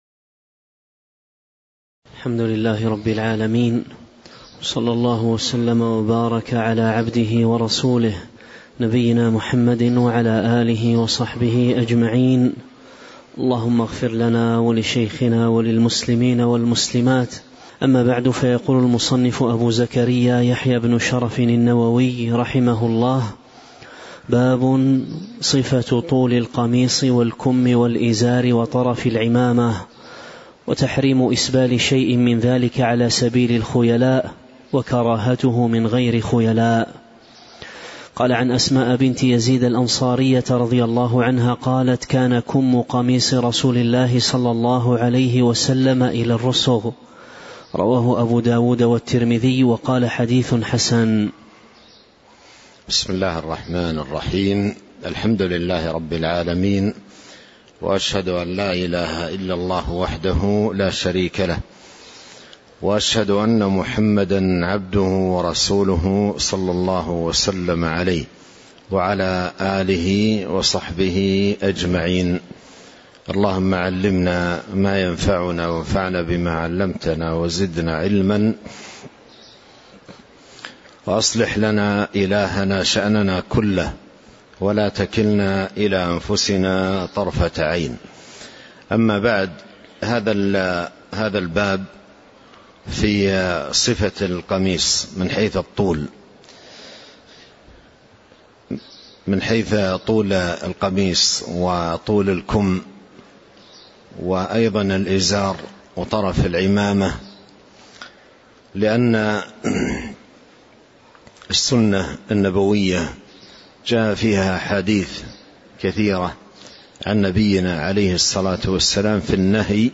تاريخ النشر ١٥ صفر ١٤٤٥ هـ المكان: المسجد النبوي الشيخ